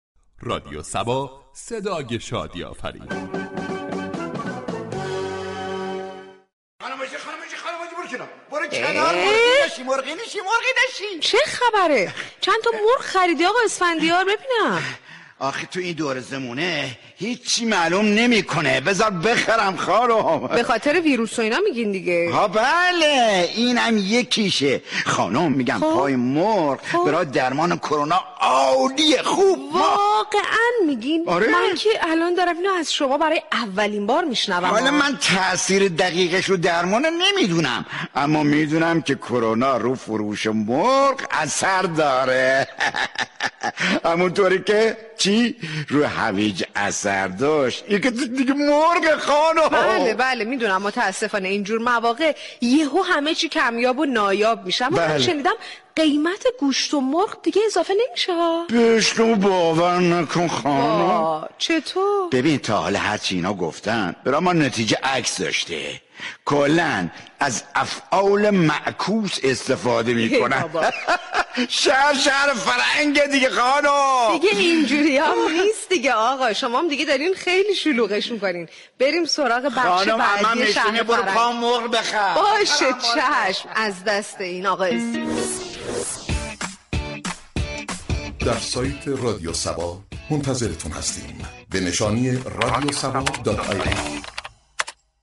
شهر فرنگ در بخش نمایشی با بیان طنز به موضوع "گرانی مواد غذایی به بهانه تاثیر در درمان كرونا "پرداخته است ،در ادامه شنونده این بخش باشید.